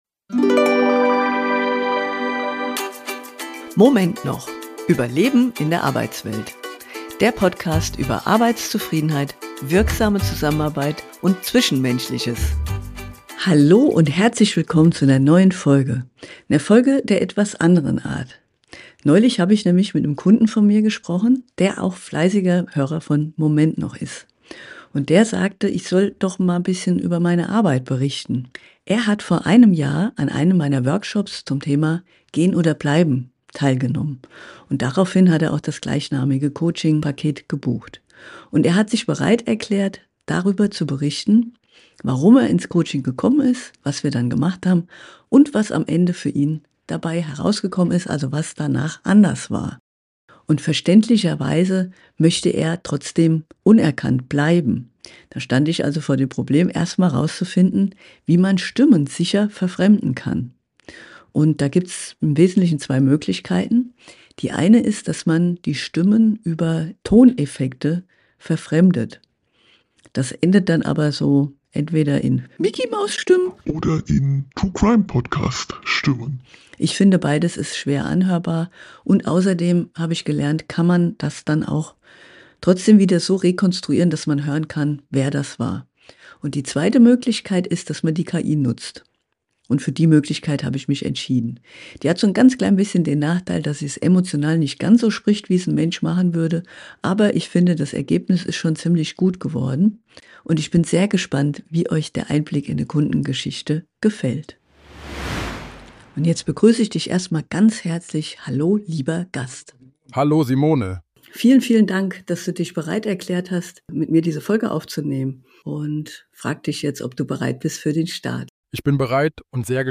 Mein Kunde erzählt in diesem Interview offen, wie groß seine Unzufriedenheit im Job war und wie er im Coaching neue Klarheit gefunden hat.